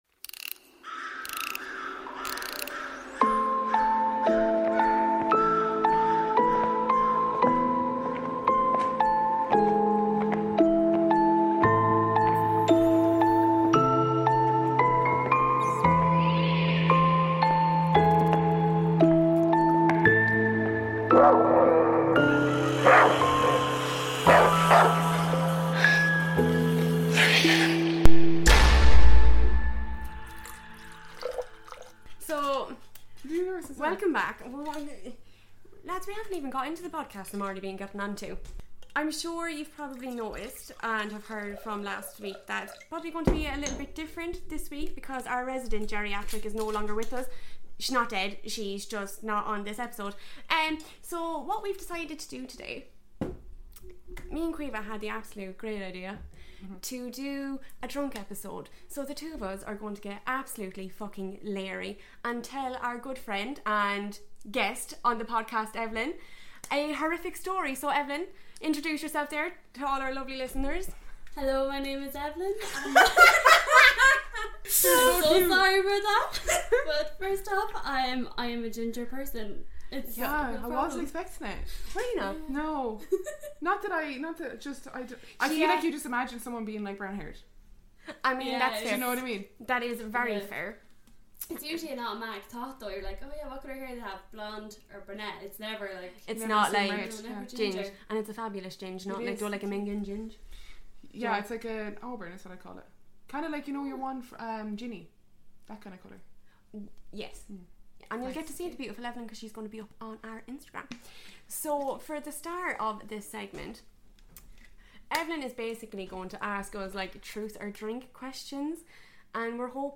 Spend the first half of this episode getting to know our hosts better with some truth or drink questions, followed down with a shot of creepy Soviet Murder. **Warning** This episode may contain stumbling over words, copious Mic wobbles, and impromptu Movie reviews.